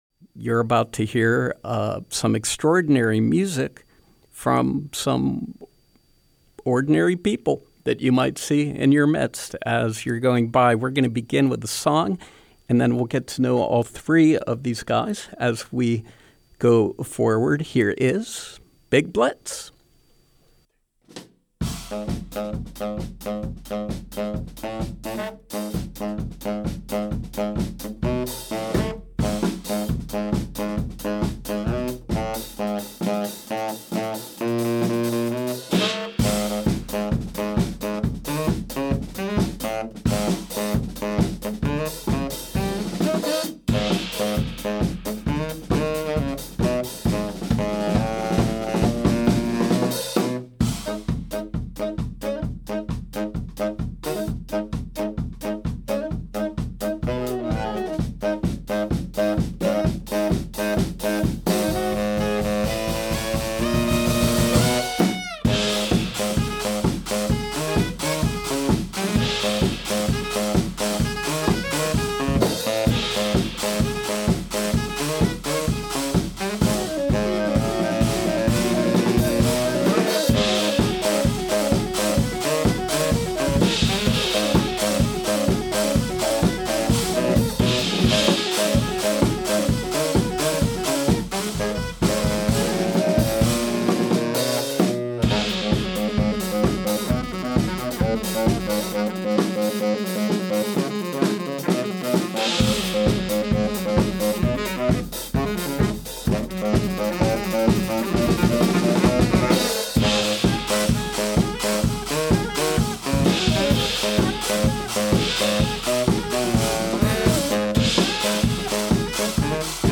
Live music and conversation
saxophone and drum trio